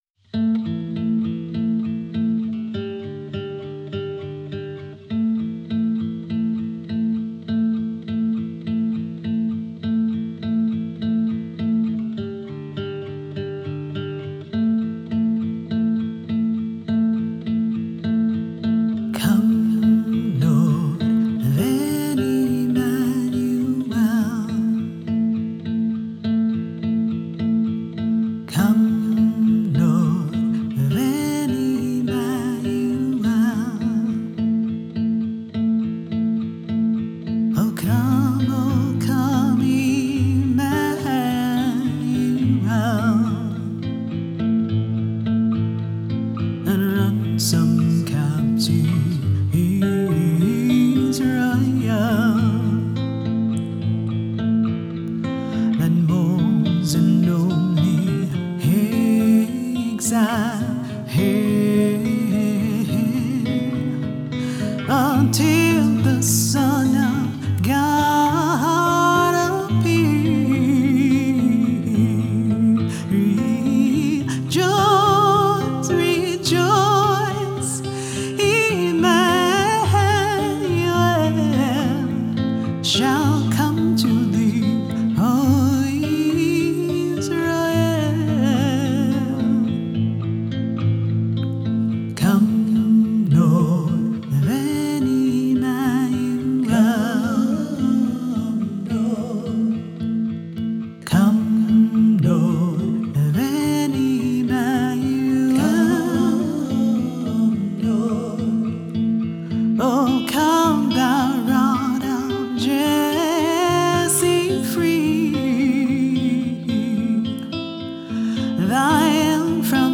Welcome to the season of Advent! This song a Color original, a new arrangement of the Christian hymn, O Come O Come Emmanuel and four of the O' Antiphons.